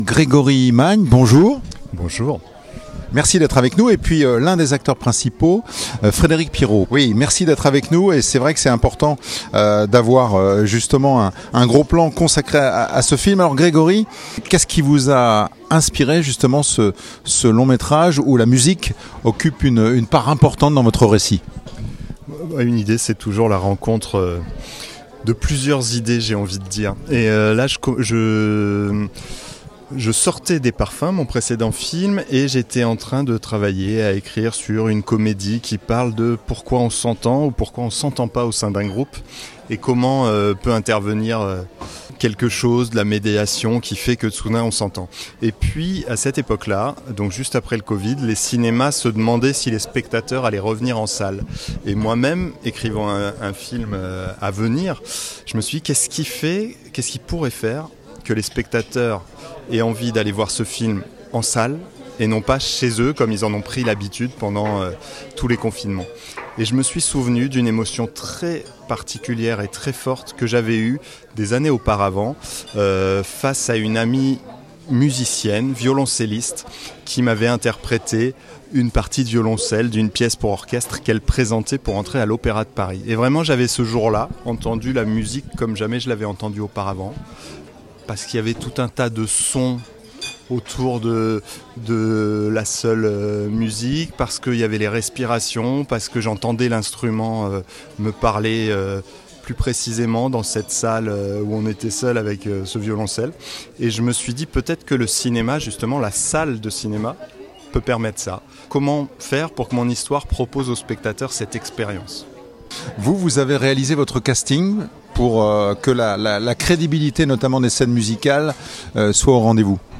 %%Les podcasts, interviews, critiques, chroniques de la RADIO DU CINEMA%% La Radio du Cinéma